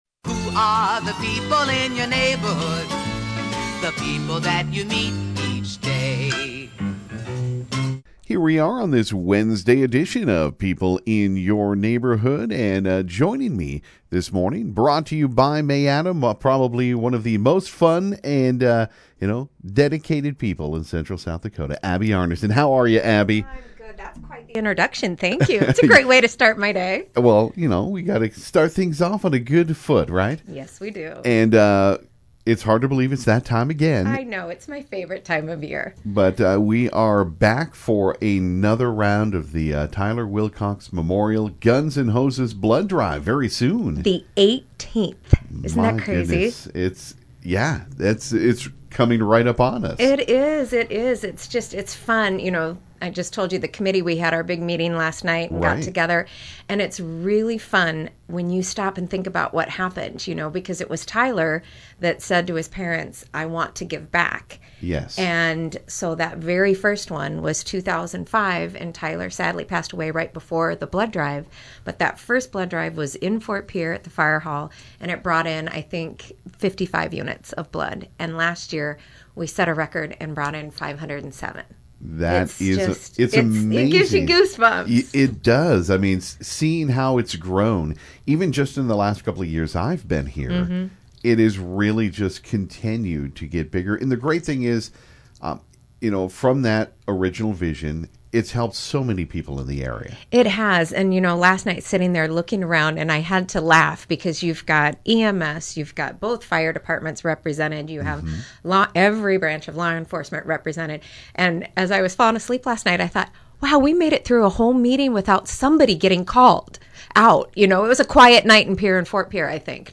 stopped by the KGFX Studio to talk about the upcoming 18th Annual Tyler Wilcox Memorial Guns & Hoses Blood Drive. It’s happening at the end of November into the beginning of December, the main difference it they are now working with LifeServe who will be collecting the blood.